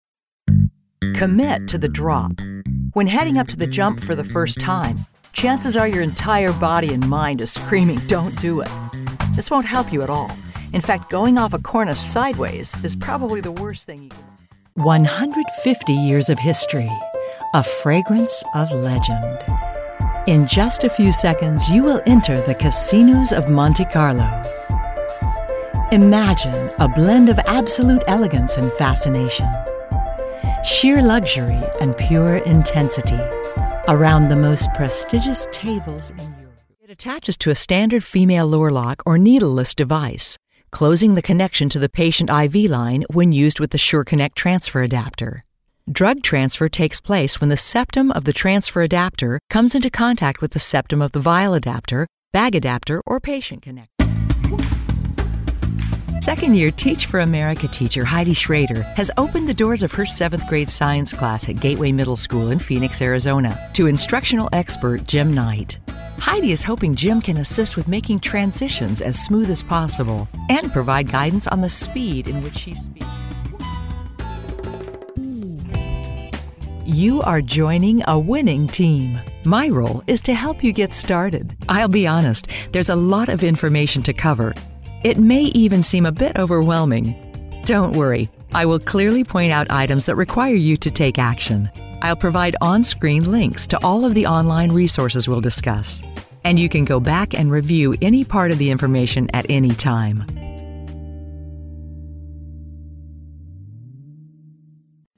Female Voice Over, Dan Wachs Talent Agency.
Professional, polished, believable
eLearning